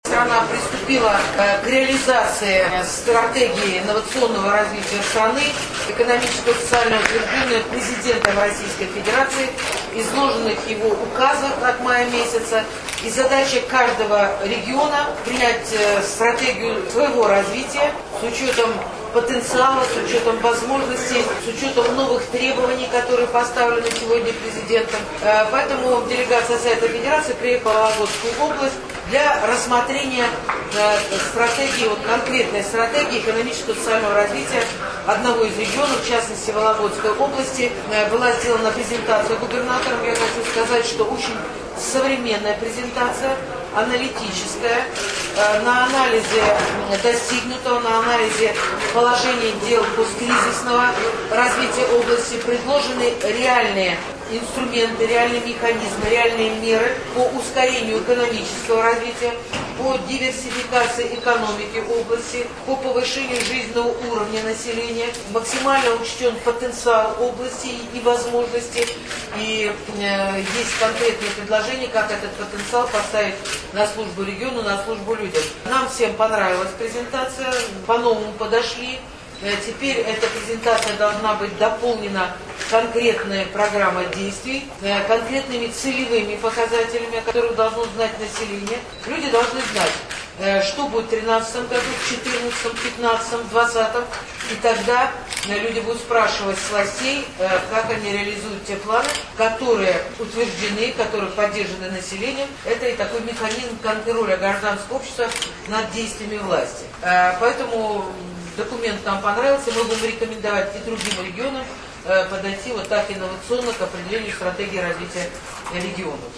Валентина Матвиенко рассказывает о стратегии развития Вологодчины